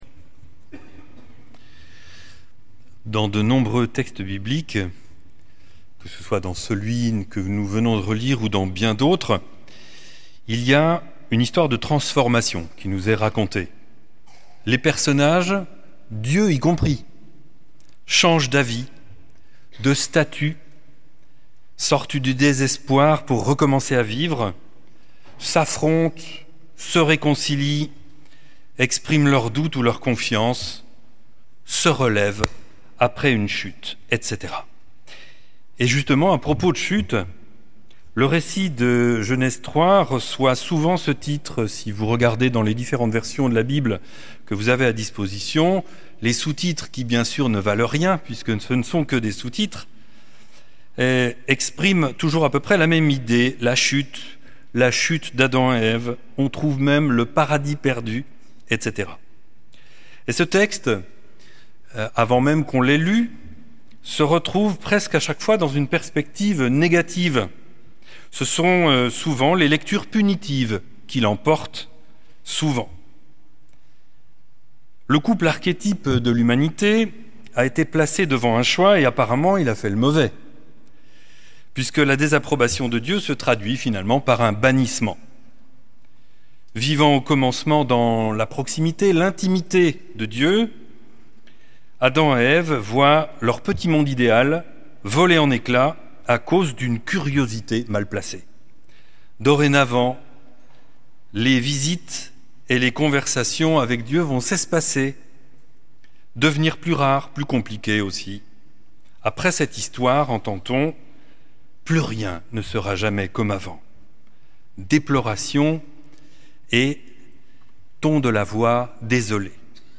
Culte à l’Oratoire du Louvre